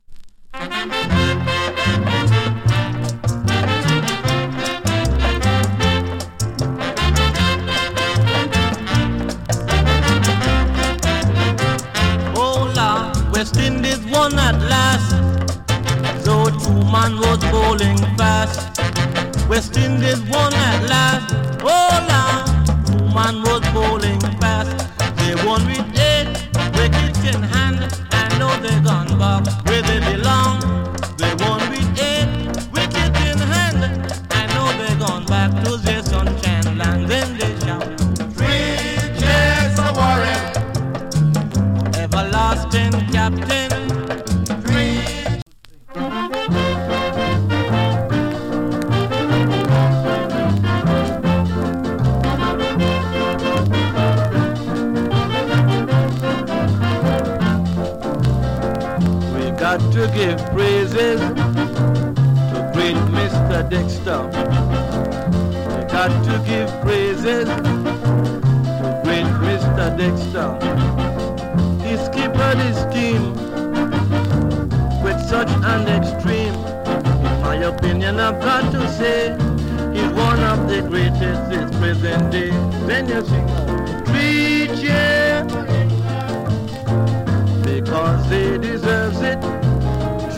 チリ、パチノイズ少し有り。
64年 FINE VOCAL CALYPSO !